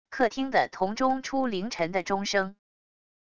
客厅的铜钟出凌晨的钟声wav音频